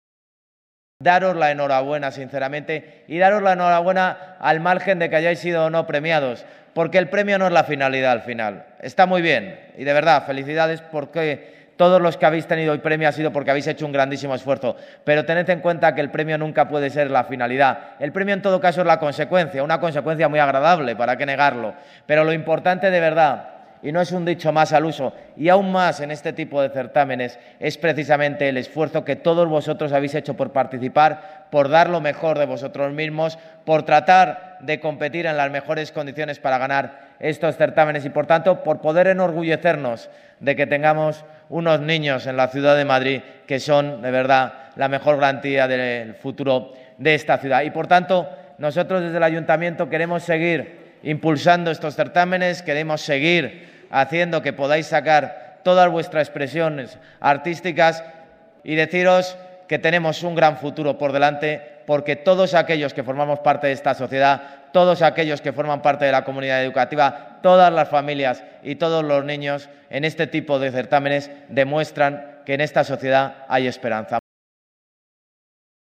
En el acto de entrega de los Premios Certámenes Escolares de prosa, poesía, plástica, cerámica, teatro y debate
Nueva ventana:Audio de Almeida en acto entrega Premios Certámenes Escolares